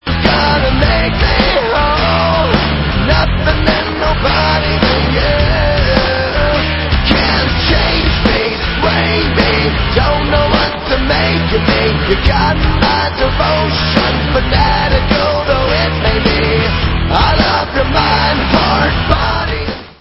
sledovat novinky v oddělení Alternative Rock
Rock